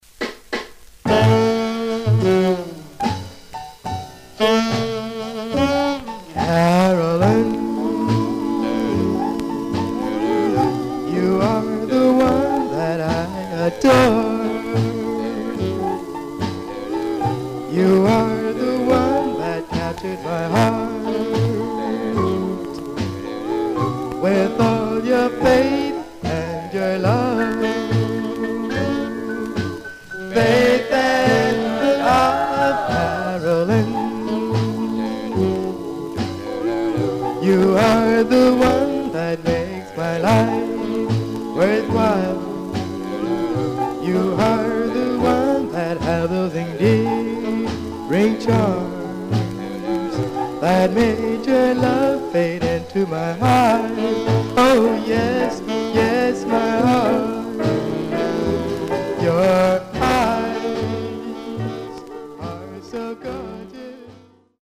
Surface noise/wear
Mono
Male Black Group